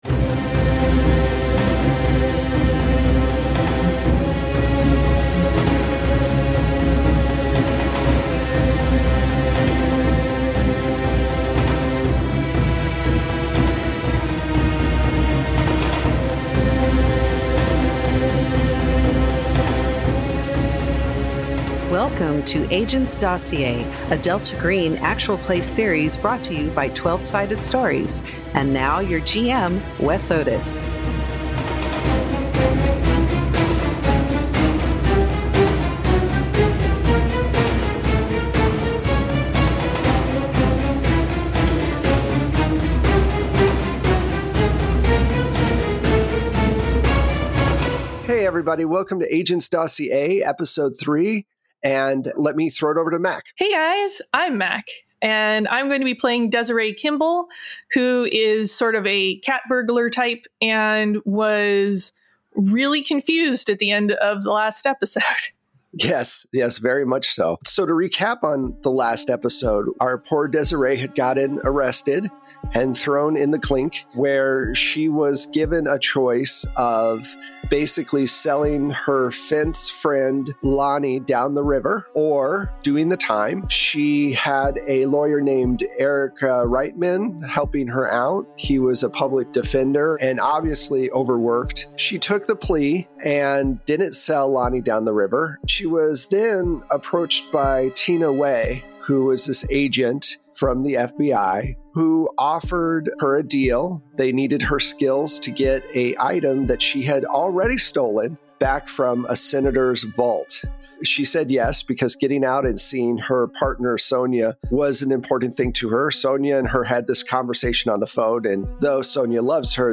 Worlds beyond imagination await you! At Twelve-Sided Stories, we bring tales to life through TTRPGs, with fully produced sound effects and music.